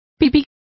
Complete with pronunciation of the translation of pees.